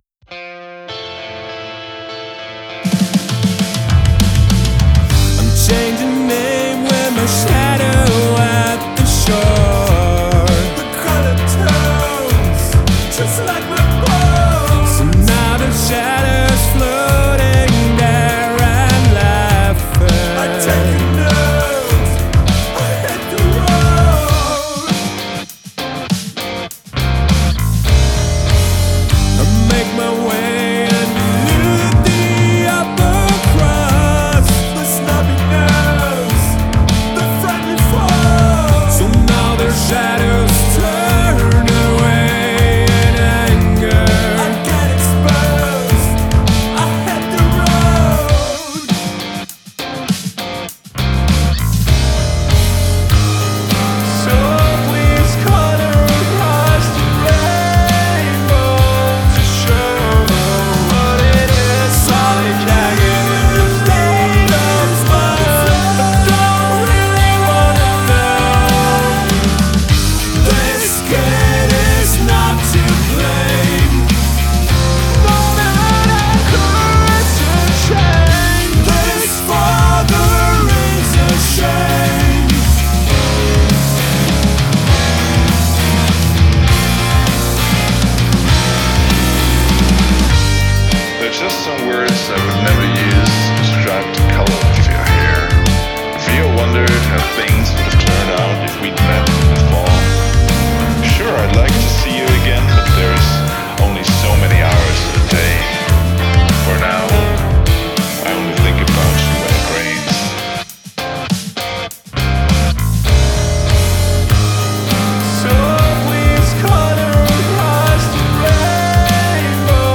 on guitar
with a charming spoken-word-part